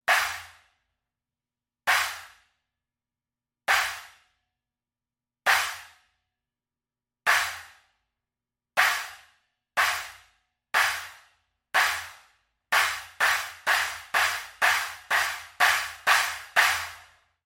Set of 3 nested / stacked cymbals 12″, 14″ and 16″.
This is our take on creating an acoustic version of a sampled clap.
We take three very thin, very warped cymbals without bells and nest them together for a unique sound.
With cymbal nut slightly loosened: